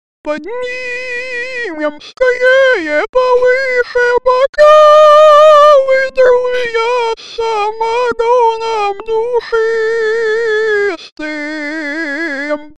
вот тут такая прога техты говорить
Прога обладает шикарным одесским акцентом, немного картавит.